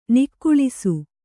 ♪ nikkuḷisu